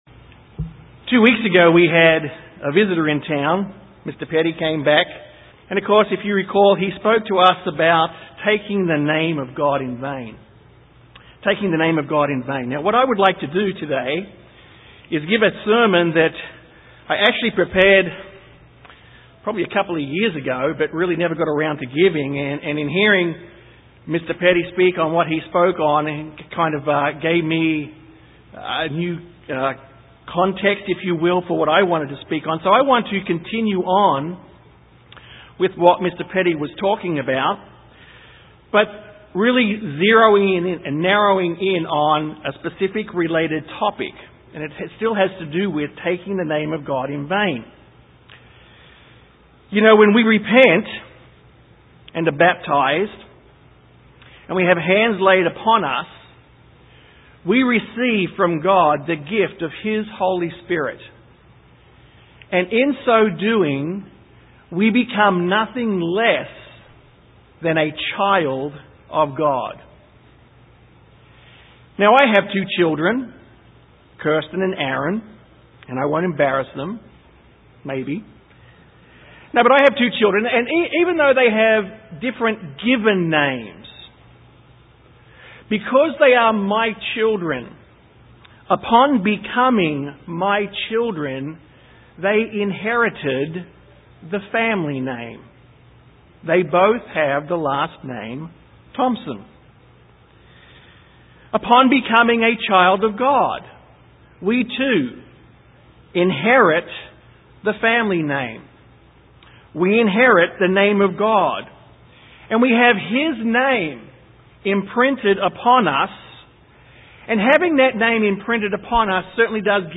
This sermon discusses the importance of keeping our word when we give it without playing "situational ethics". For a Child of God, who bears the Name of God, to go back on our word is to take the Name of God in Vain.
Given in San Antonio, TX